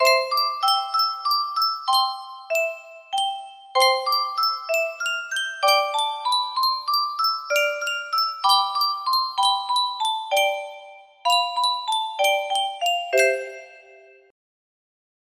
Yunsheng Music Box - Unknown Tune 1522 music box melody
Full range 60